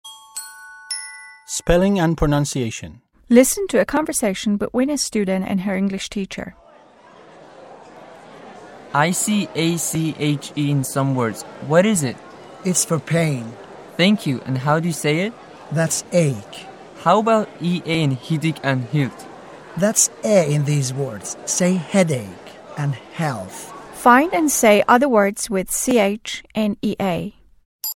4-english-8-4-spelling-and-pronunciation.mp3